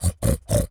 pig_sniff_07.wav